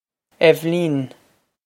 Eibhlín Ev-leen
This is an approximate phonetic pronunciation of the phrase.